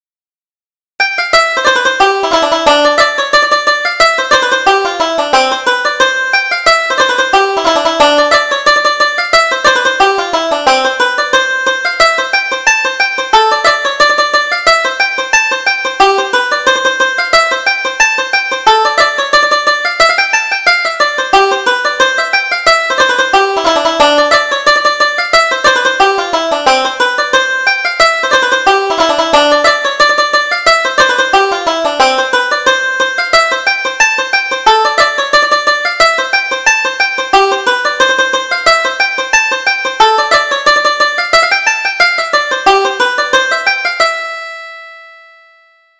Reel: A Moonlight Ramble